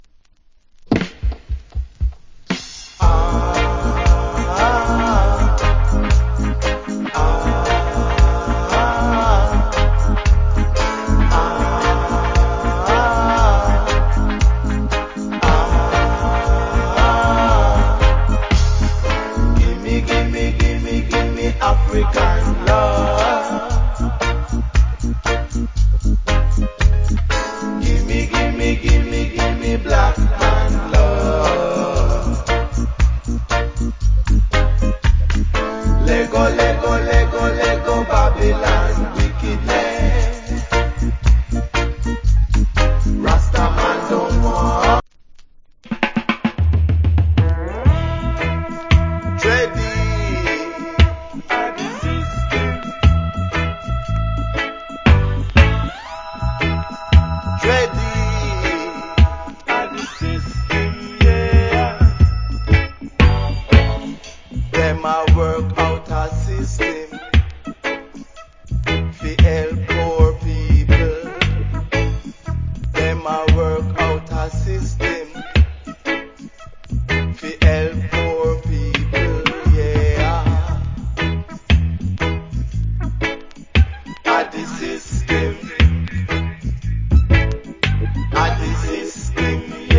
Cool Roots Rock Vocal + Nice Dub.